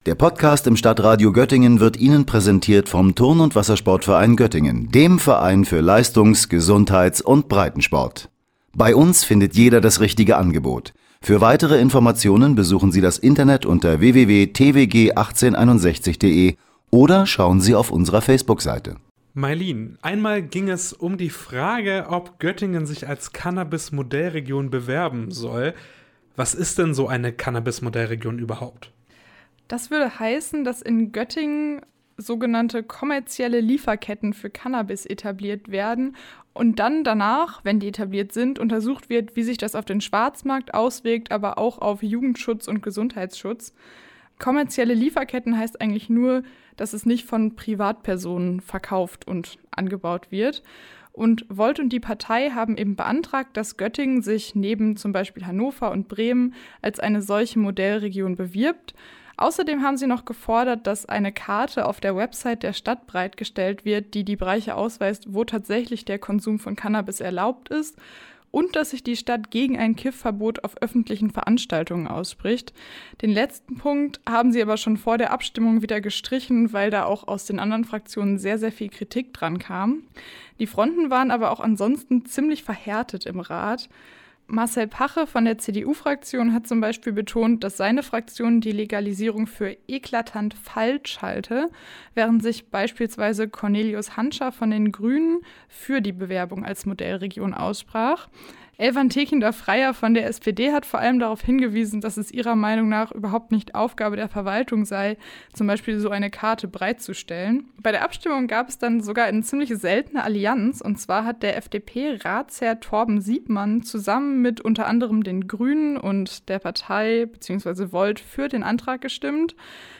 im Gespräch berichtet, was der Rat beschlossen hat.